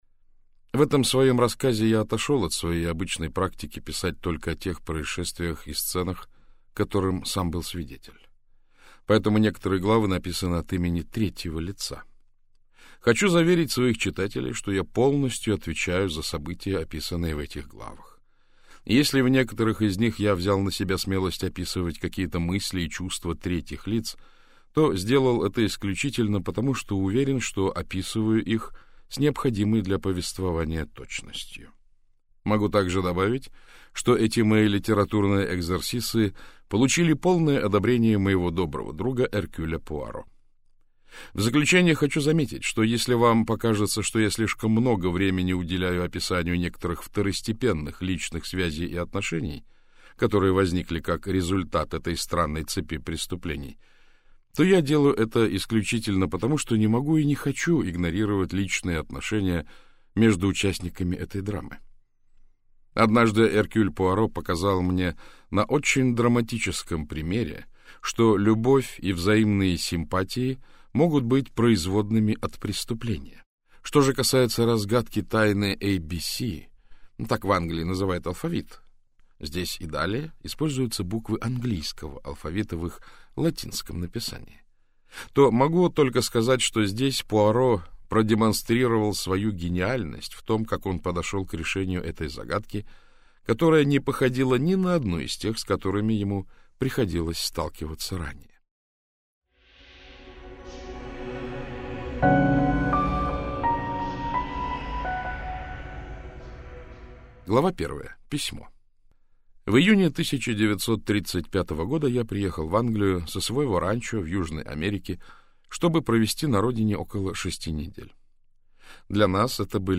Аудиокнига Убийства по алфавиту - купить, скачать и слушать онлайн | КнигоПоиск